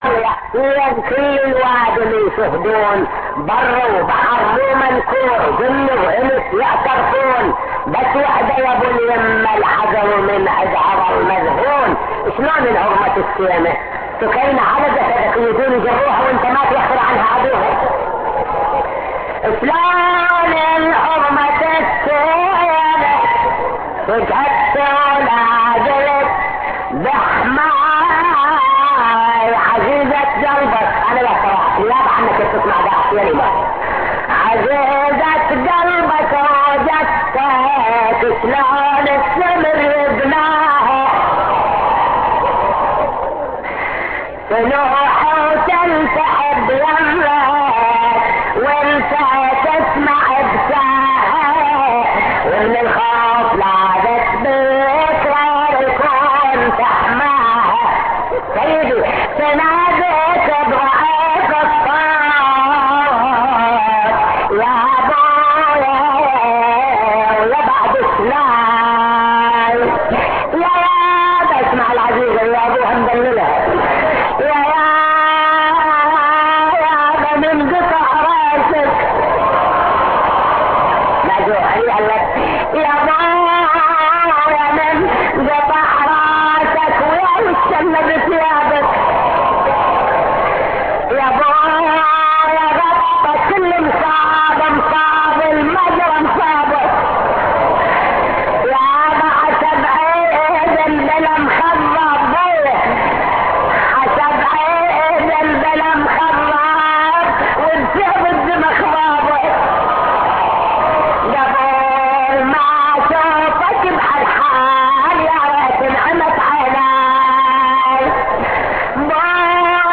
نواعي حسينية 2